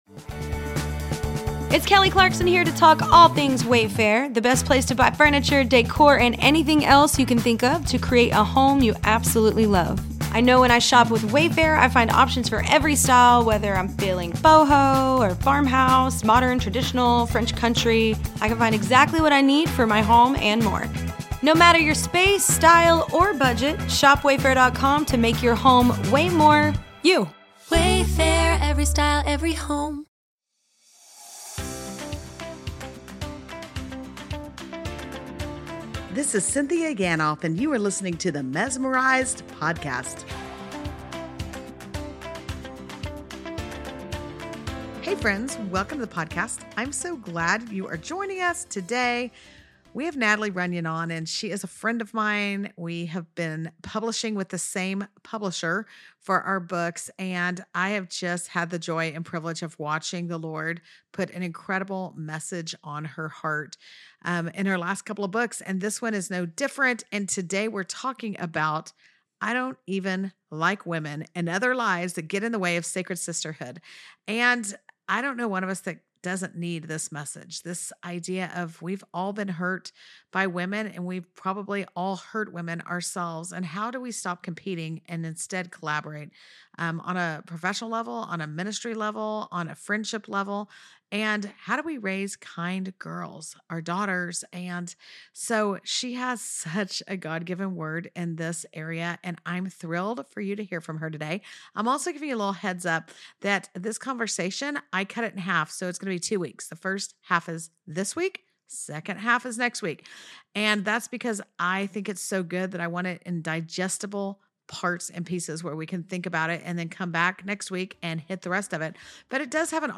This is part one of a two-part conversation, and in this first half we talk about what it looks like to stay obedient to God even when criticism comes, the lies women believe about themselves and each other, and how those scripts often keep us competing instead of collaborating. We laughed about the stereotypes we grew up with in sitcoms and movies, but we also dug deep into how much identity shapes our ability to form healthy friendships and truly cheer each other on.